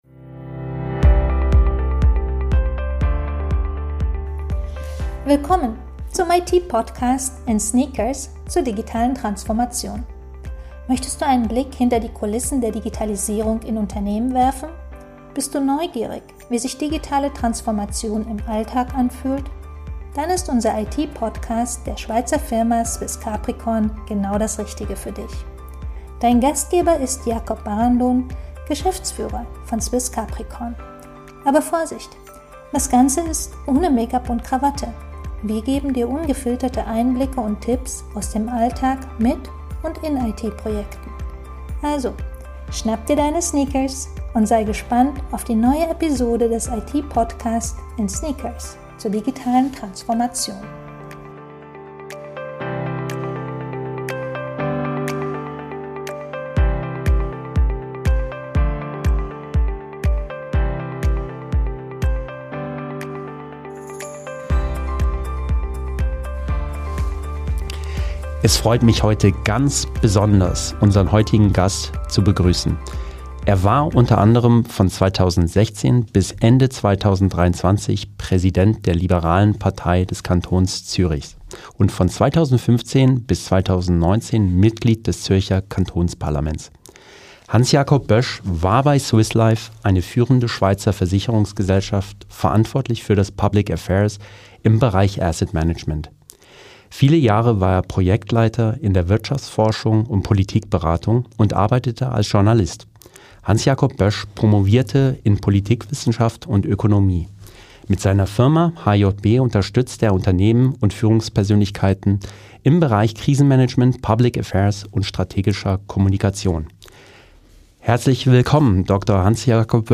#6 - Interview mit Dr. Hans-Jakob Boesch ~ CapricornConnect: People, Potential, Technology.
Wie du deine Firma vor Reputationsschäden schützt. Ein ehemaliger Politiker gibt wertvolle Tipps für Risikomanagement und aktive Kommunikation.